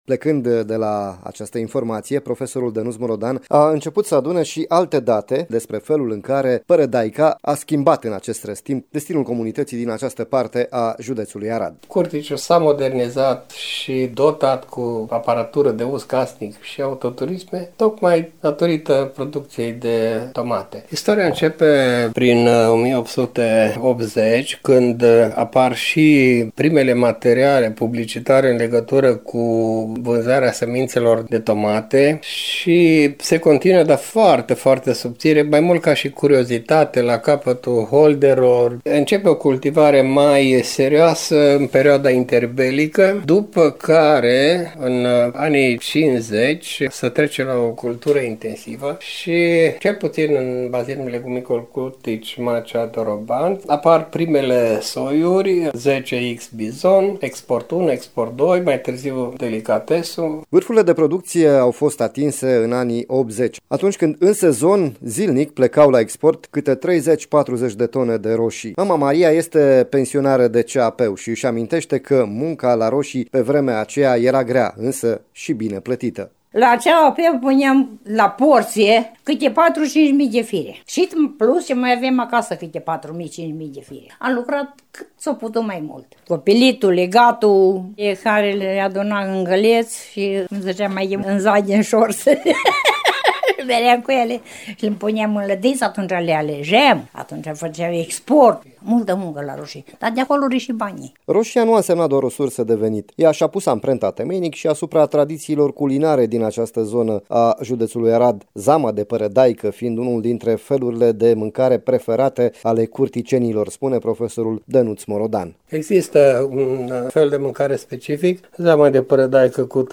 Povestea părădăicilor relatată de oamenii locului o puteți asculta